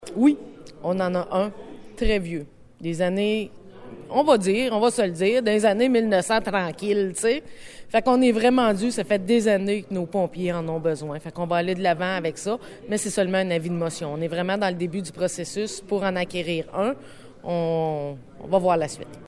En effet, mardi soir dernier, un avis de motion a été donné afin de décréter un emprunt en ce sens. Voici les propos de la mairesse, Anne Potvin :